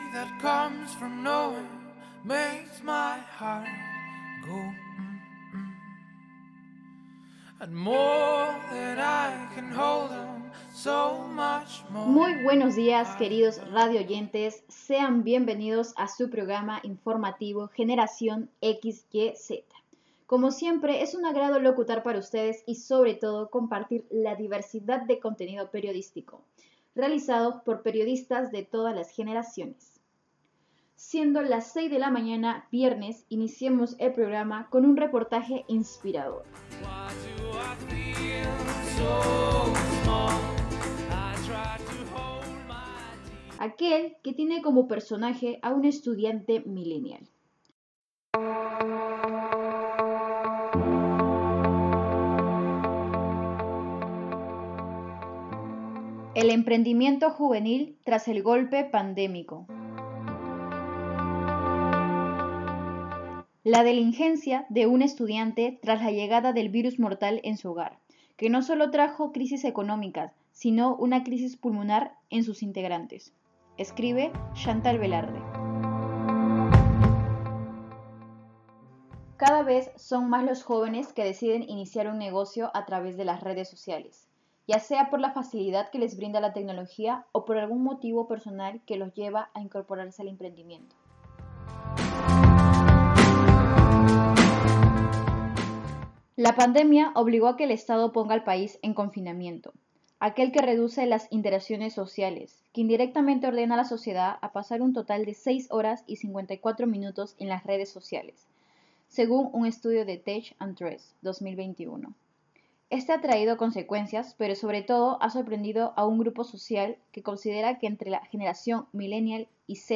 REPORTAJE RADIAL